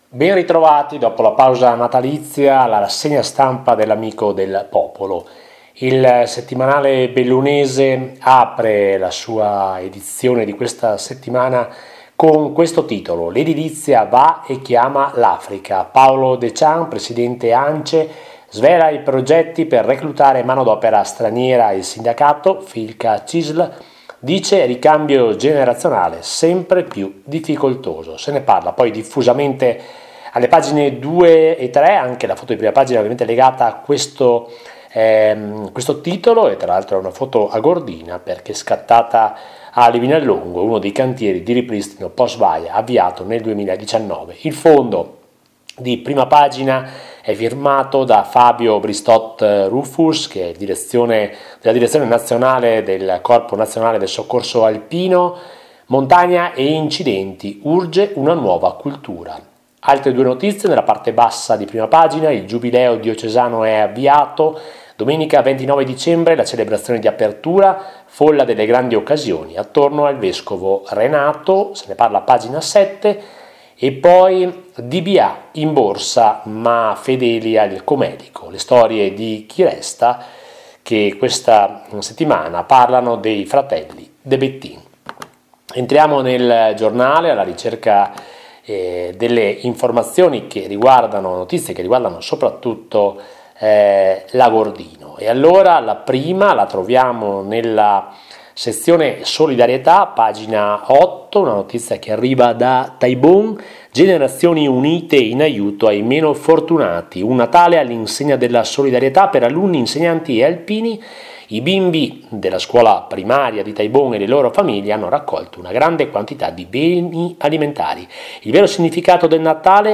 LA RASSEGNA STAMPA DA L’AMICO DEL POPOLO – 11 GENNAIO 2025